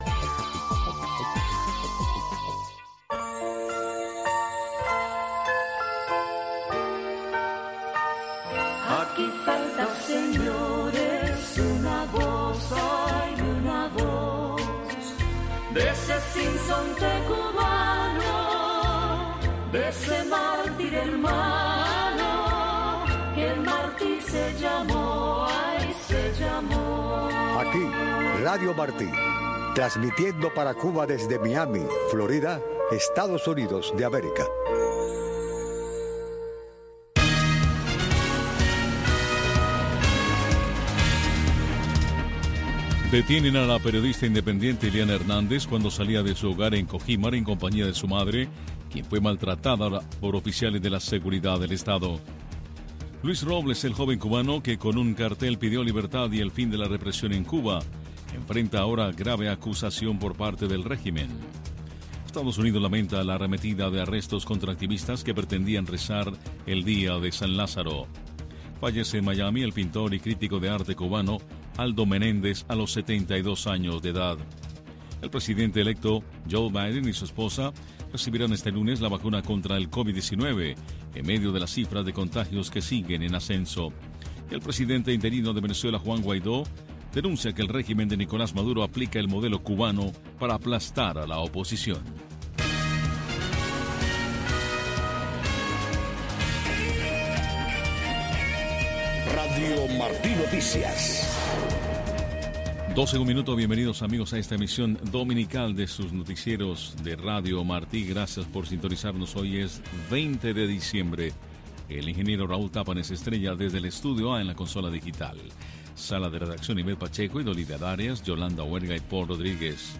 Noticiero de Radio Martí